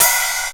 Index of /m8-backup/M8/Samples/Fairlight CMI/IIX/CYMBALS
HIHAT3.WAV